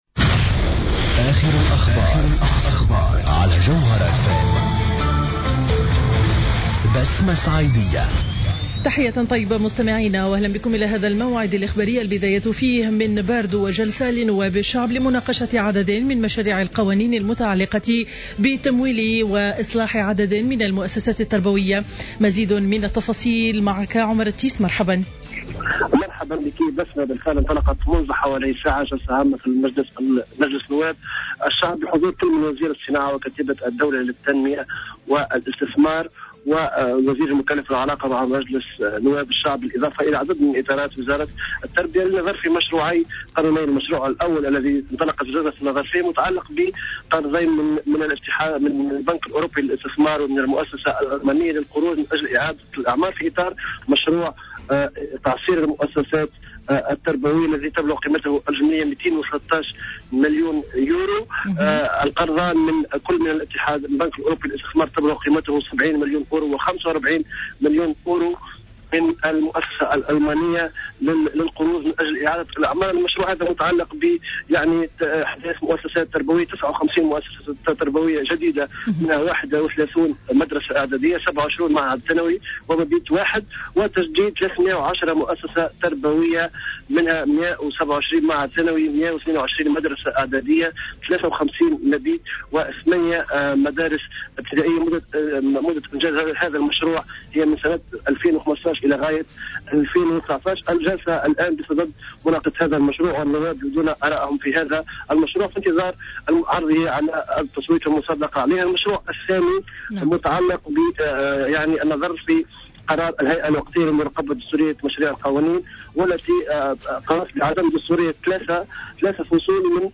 نشرة أخبار منتصف النهار ليوم الأربعاء 1 أفريل 2015